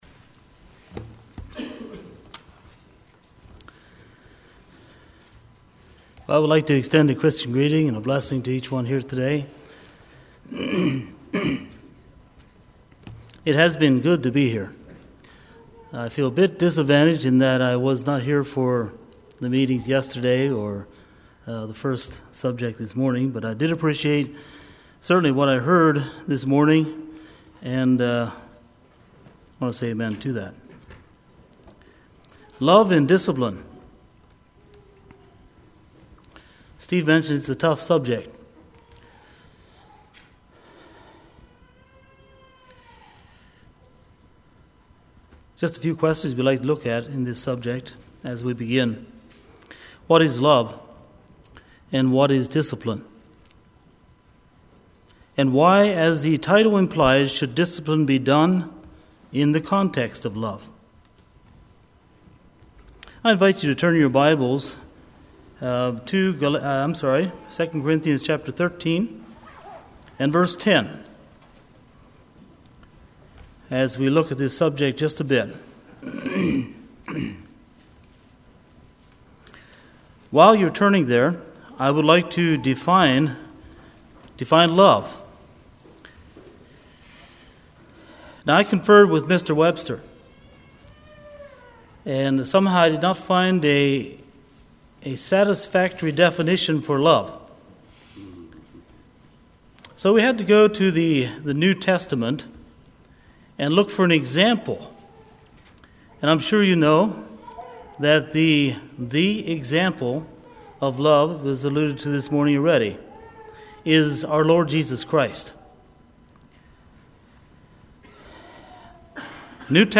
2018 Sermon ID